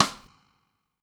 stick 2.wav